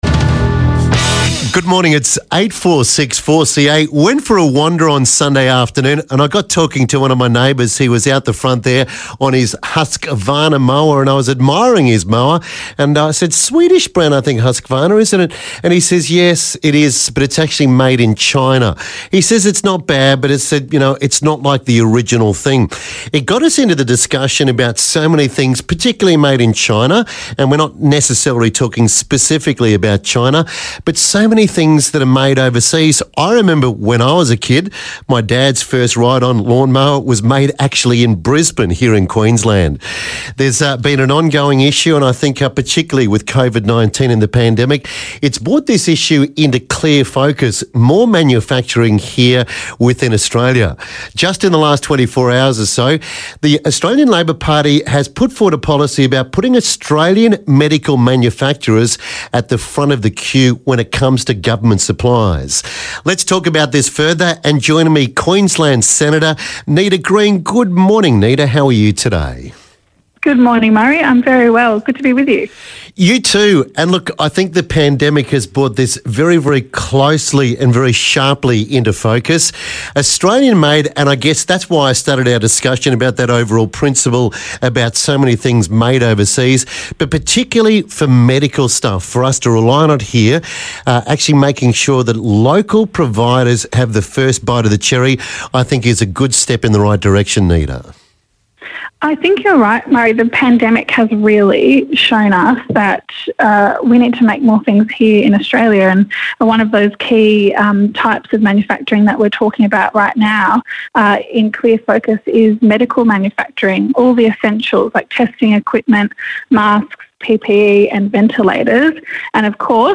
speaks with Queensland Senator Nita Green about an initiative to ensure that Australian made medical supplies are given purchasing priority over foreign made items